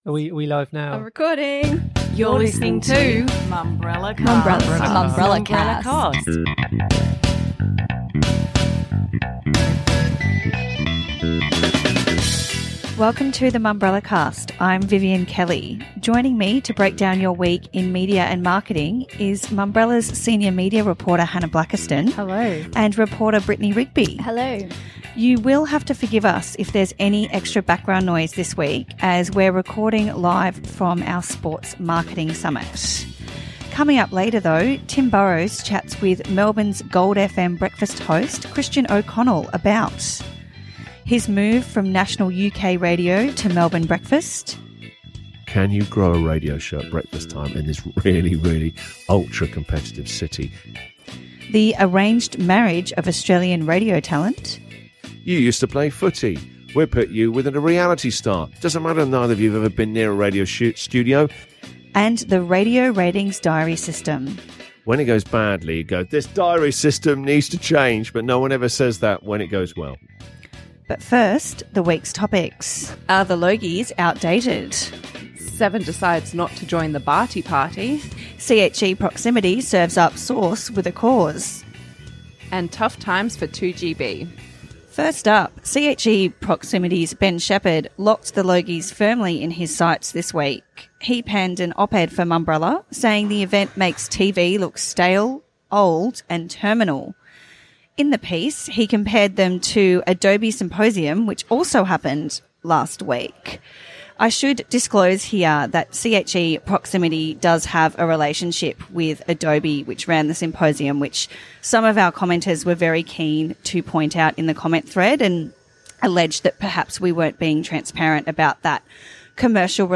Also in this week’s episode, the team attempts to find the quietest corner they can at Mumbrella’s Sports Marketing Summit to talk about why Seven wanted Australians to join the Barty Party with its Wimbledon coverage… and then failed to put it on air.